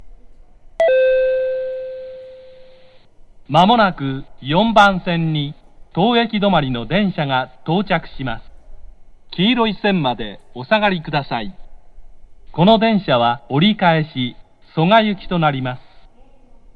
発車メロディー
●スピーカー：National天井丸型
●音質：良